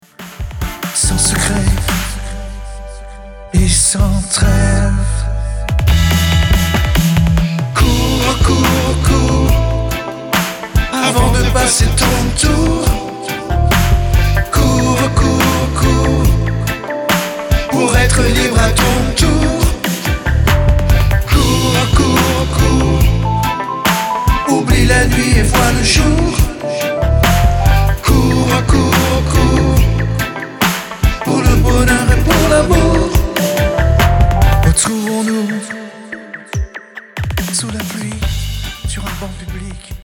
ballade reggae douce et profonde
Avec son rythme reggae lent et enveloppant .